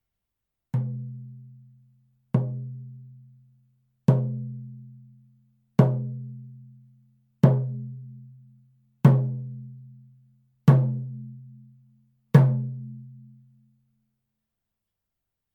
FRAME DRUM Native American (Indian) style
素材：牛革・天然木
パキスタン製フレームドラム 音